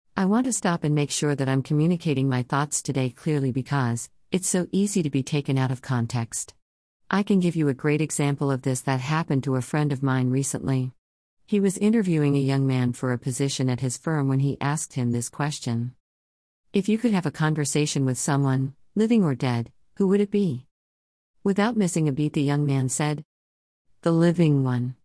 There are two audio versions of the joke — one male voice and one female voice using a more realistic TTS (Talk To Speech) technology in order to provide neutral audio examples as spoken by different genders.
Transformed Joke Example (Female Voice)